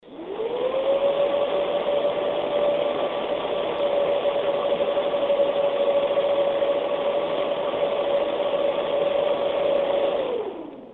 Abstract: Gigabyte's Volar heatsink has a 120mm smokey grey fan has rather large impeller blades that look as if they'll propel air into the cooling fins of its donut shape heatsink with quiet force.
The Gigabyte Volar heatsink is moderately audible at full speed, and reduced speed.
frostytech acoustic sampling chamber - full speed
standard waveform view of a 10 second recording. click on the headphones icon to listen to an mp3 recording of this heatsink in operation. the fan is rotating at 2000 rpm.